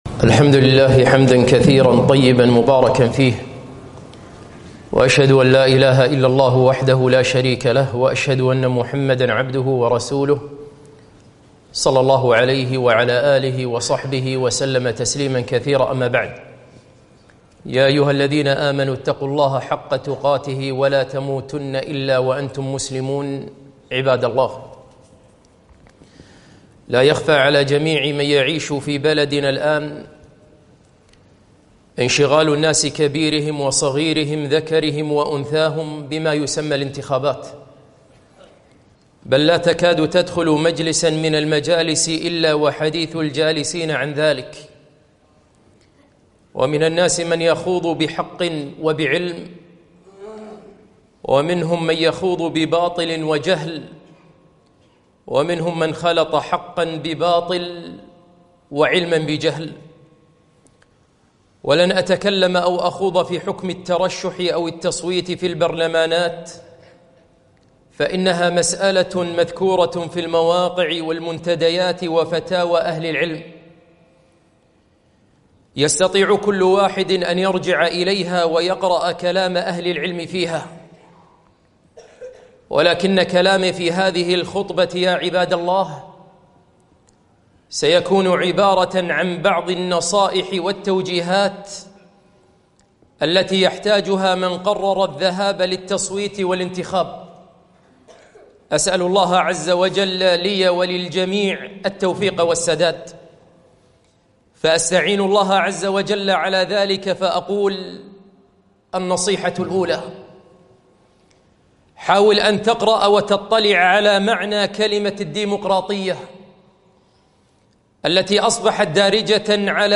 خطبة - نصائح قبل التصويت لمجلس الأمة